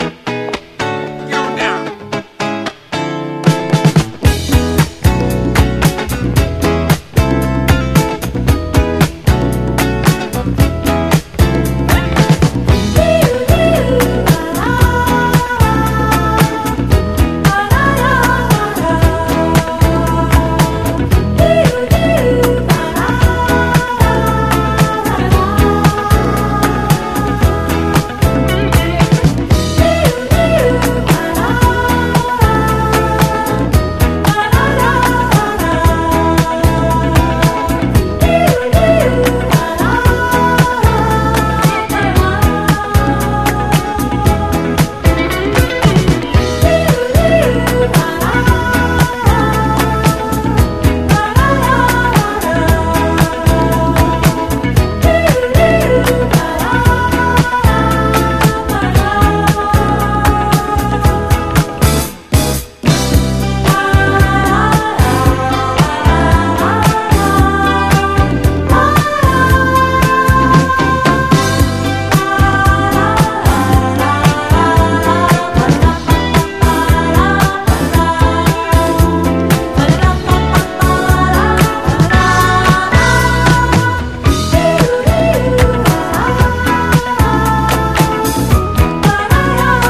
フリー・ソウルなアーバン・ディスコ・グルーヴ人気曲
粋なギター・カッティングに気持ちよく決まるホーン・セクションも最高、華麗な女性ヴォーカルも素敵な大ヒットした